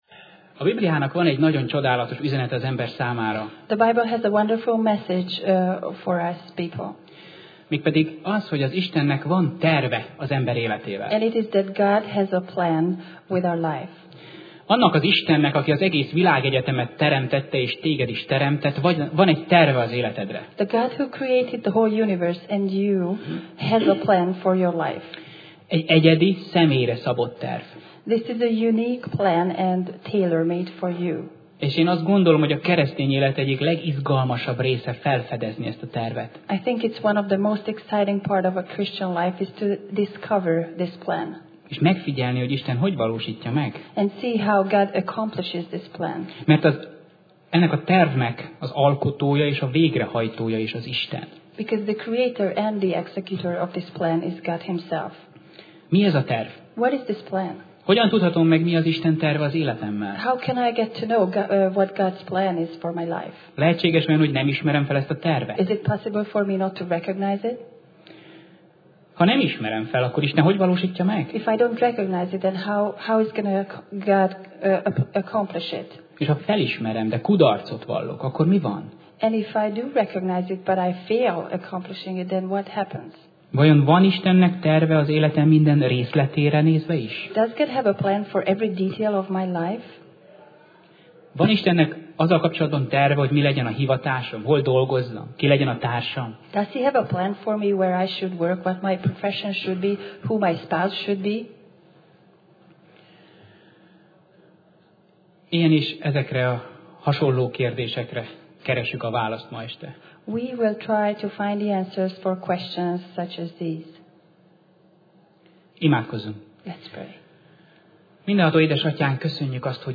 Sorozat: Tematikus tanítás Alkalom: Szerda Este